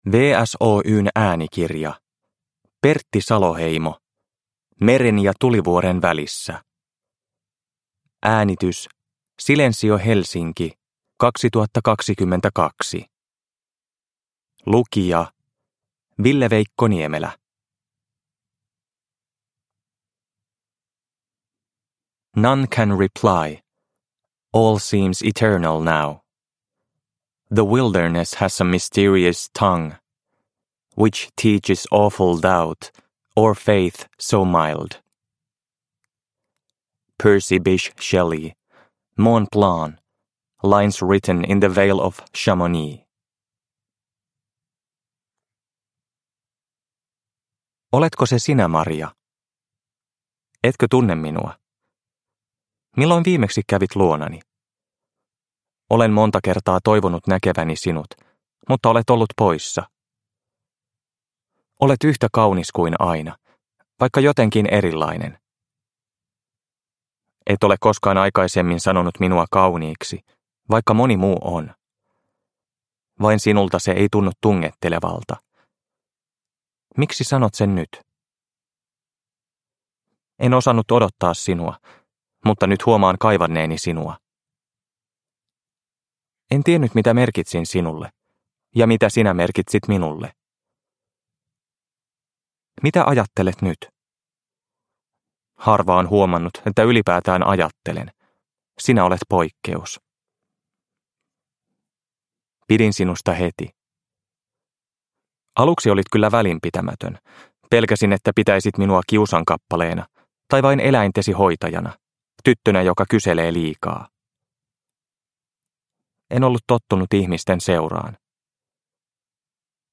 Meren ja tulivuoren välissä – Ljudbok – Laddas ner